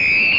Whistle Sound Effect
Download a high-quality whistle sound effect.
whistle-1.mp3